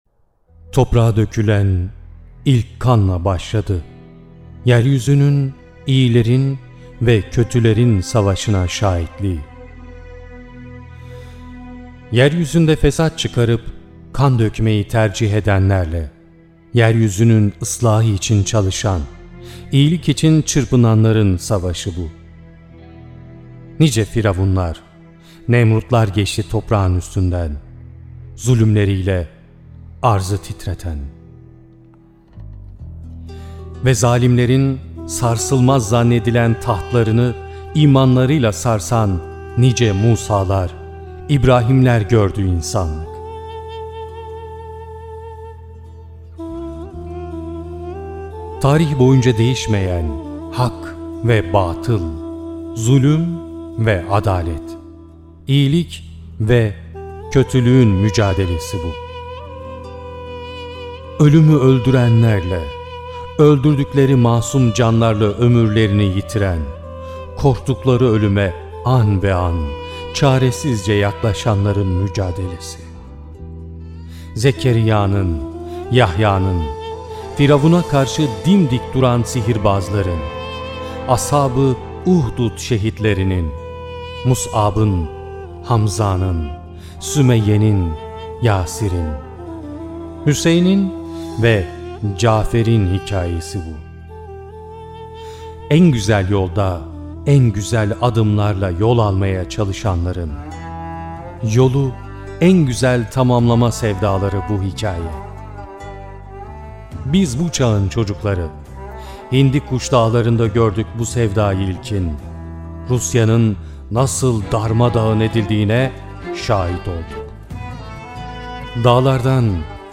29 Şubat Cumartesi günü yapmış olduğumuz “Şehitler Günü” programında göstermiş olduğumuz sinevizyon.